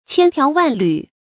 千條萬縷 注音： ㄑㄧㄢ ㄊㄧㄠˊ ㄨㄢˋ ㄌㄩˇ 讀音讀法： 意思解釋： 形容條縷繁多。